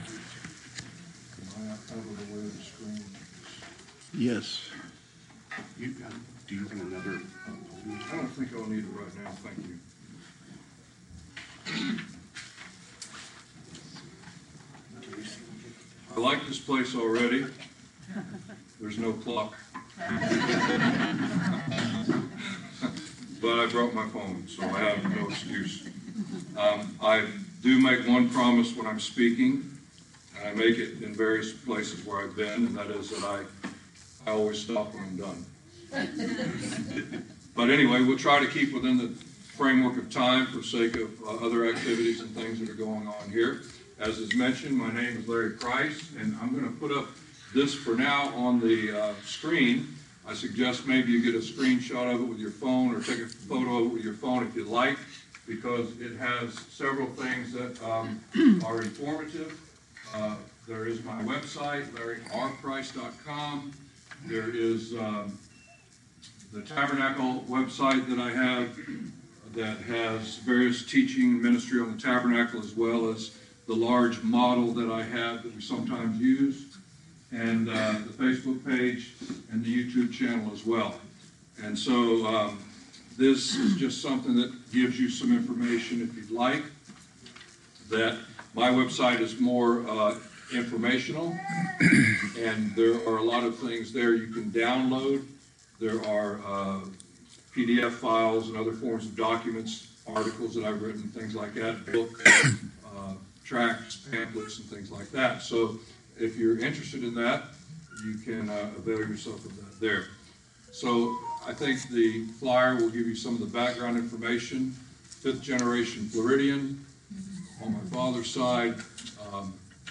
Held March 8, 2025, at Royal Orleans Banquet Center.